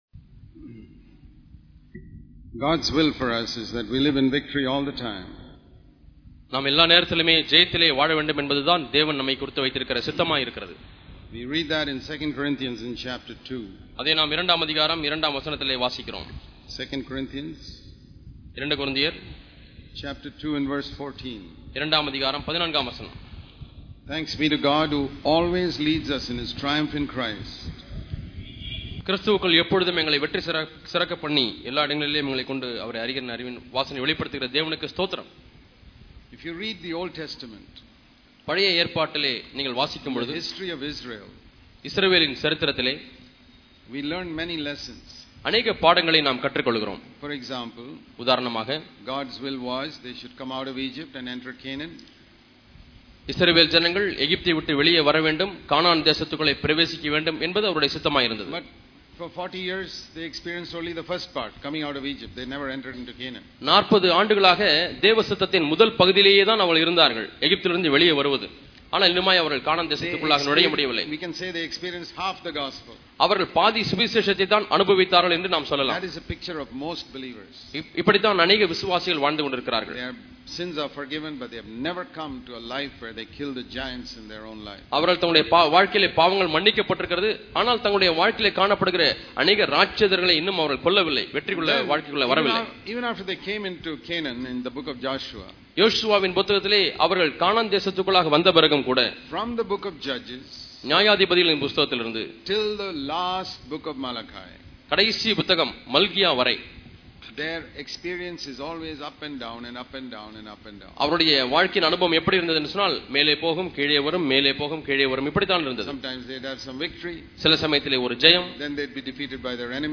Constant Victory Is God's Will Heavenly Life on Earth Click here to View All Sermons இத்தொடரின் செய்திகள் தேவ சித்தம் தான் தொடர் வெற்றிவாழ்வாழ்வக்கு வழியா?